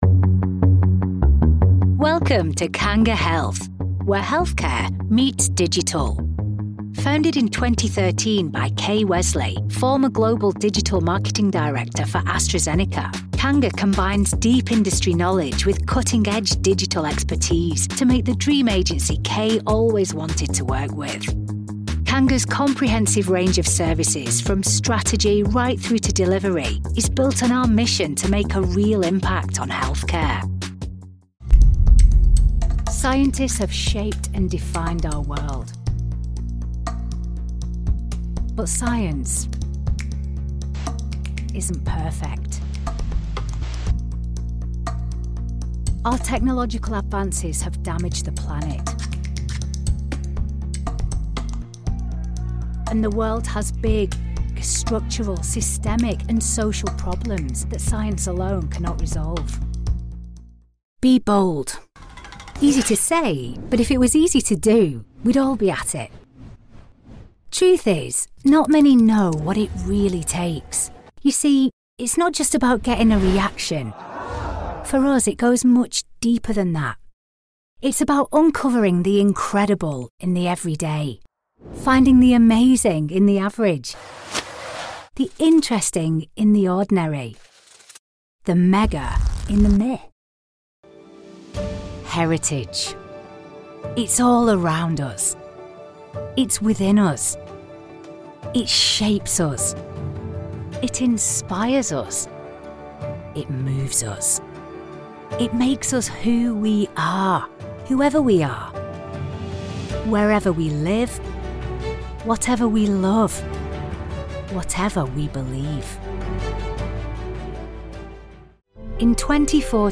Natürlich, Unverwechselbar, Vielseitig, Freundlich, Warm
Unternehmensvideo
A naturally northern voice that CONNECTS with warmth, depth and authenticity.